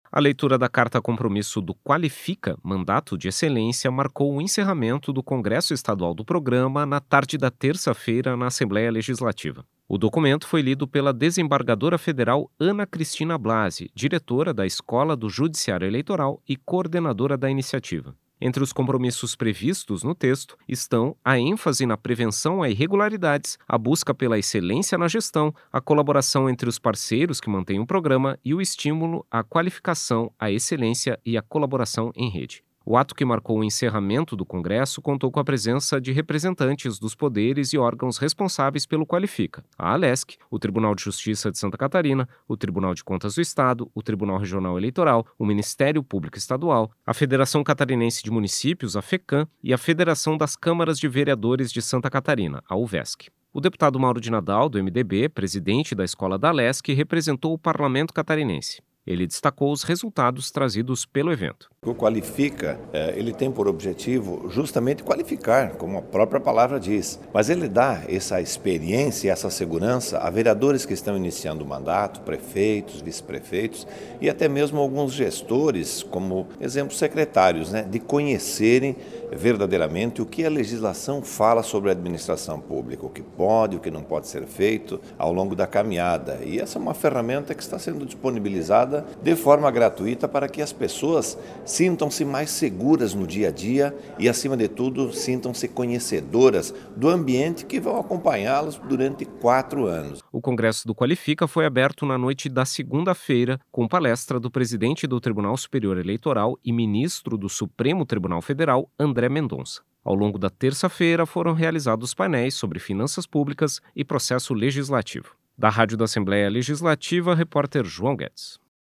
Entrevista com:
- deputado Mauro de Nadal (MDB).